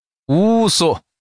Index of /hunan_master/update/12815/res/sfx/changsha_man/